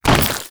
SPLAT_Crunch_Crack_01_mono.wav